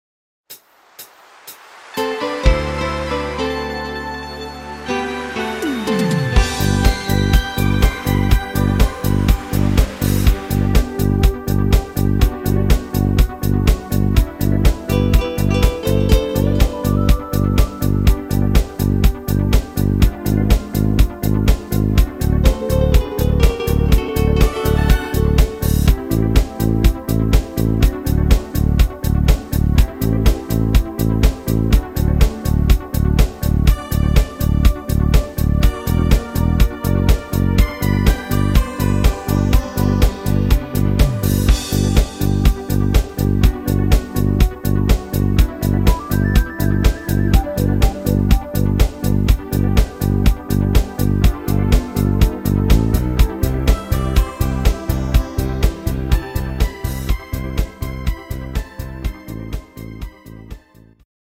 fetzige Discofox Version